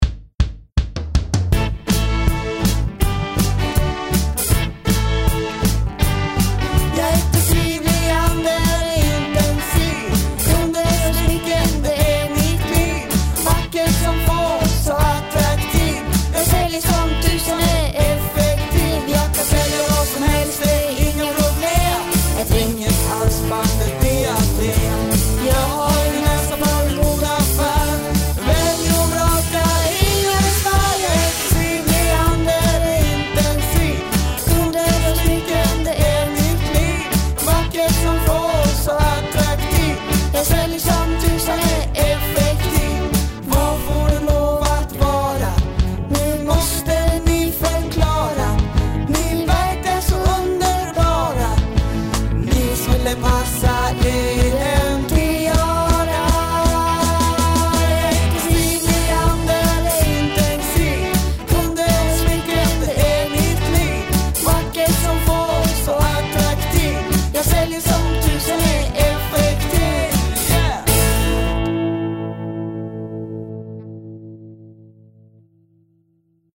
Sångversion